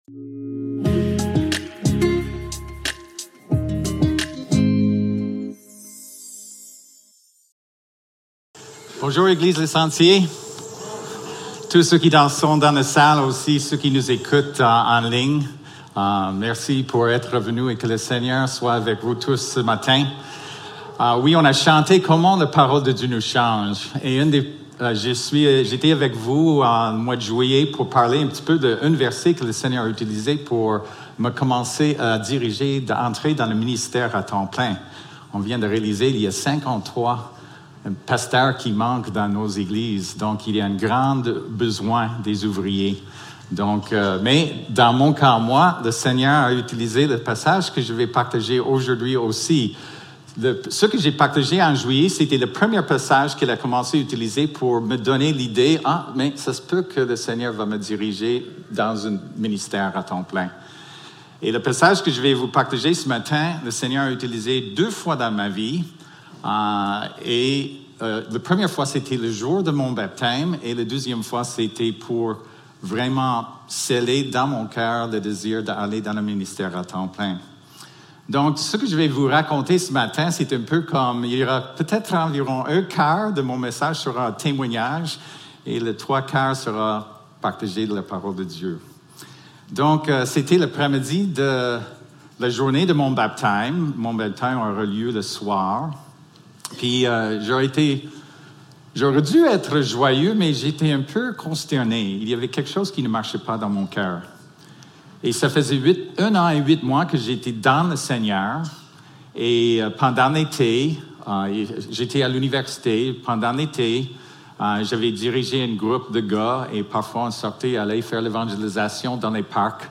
Service Type: Célébration dimanche matin